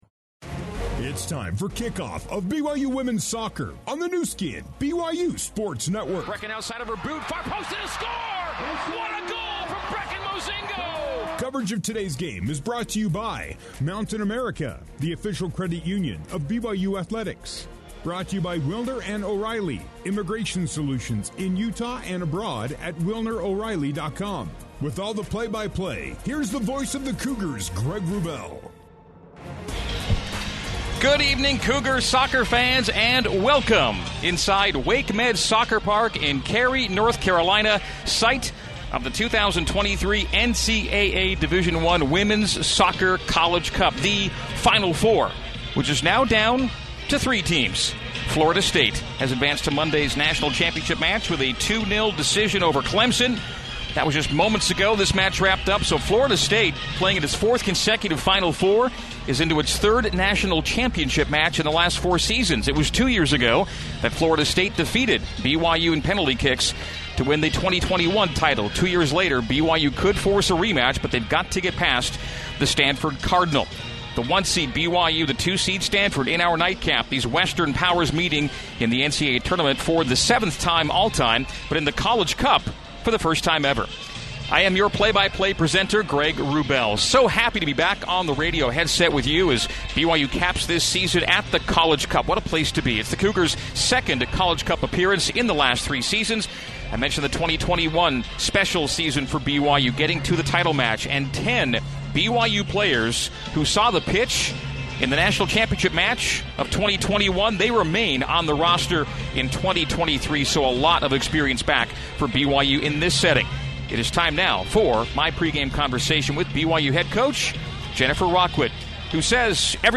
Enjoy archived radio play-by-play broadcasts of BYU women's soccer games!